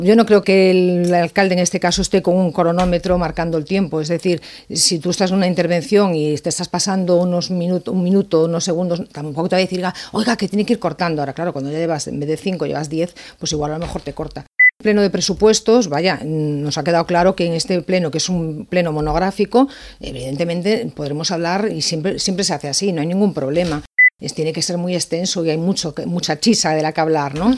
En una entrevista a Ràdio Calella TV, Coronil ha criticat que la mesura s’hagi aprovat “per decret” i sense debat polític, i alerta de l’impacte que pot tenir sobre els serveis municipals.